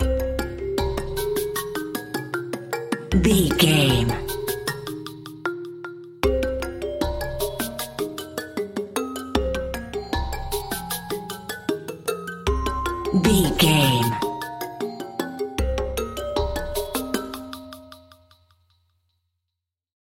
Aeolian/Minor
bongos
congas
Claves
cabasa
hypnotic
medium tempo